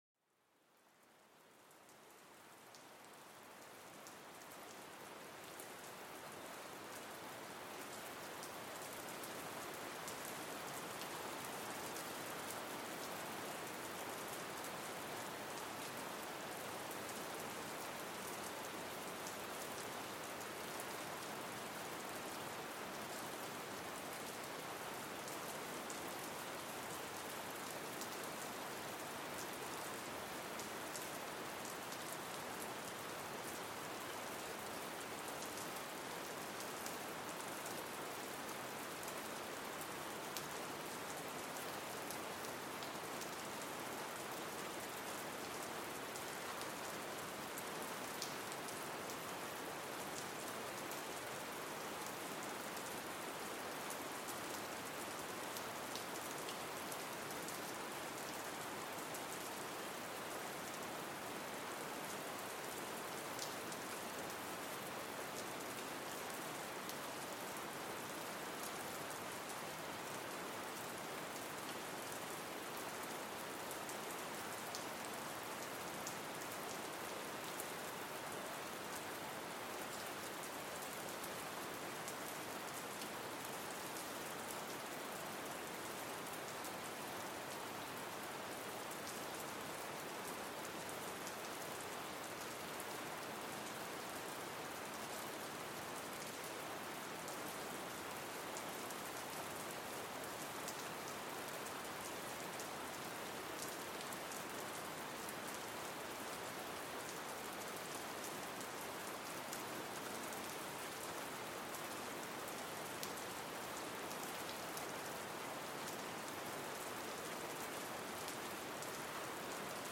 En este episodio, déjate envolver por el sonido calmante de una lluvia ligera, creando una atmósfera de tranquilidad sin igual. Cada gota cuenta una historia, una canción de cuna para el alma que busca calma y serenidad. Perfecto para un descanso meditativo o para acompañar tus momentos de relajación, este viaje auditivo es una invitación a reconectar con la suavidad de la naturaleza.Ofreciendo una escapada auditiva al corazón de la naturaleza con grabaciones de alta calidad de los sonidos naturales, este podcast revela el poder relajante e inspirador de la naturaleza.